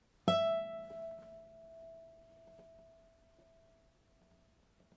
古典吉他。单音非颤音弦1：E7B8 " 古典吉他A7弹拨非颤音
描述：这是一个古典吉他弹奏第七个八度的A音的乐器样本。这个音符的攻击和延音是弹拨式的，非振动式的。使用的调谐频率（音乐会音高）是440，动态意图是夹音。这个样本属于一个多样本包 乐队乐器。古典吉他乐器。chordophone和弦琴。弹拨乐器音符：A八度。7音乐会音高：440Hz动态：Mezzoforte攻击。拨动持续。非振动麦克风。ZoomH2N话筒设置。XY
标签： 多重采样 放大H2N 非颤音 弹拨 mezzoforte A-7 弹拨仪器 弦鸣乐器 古典吉他
声道立体声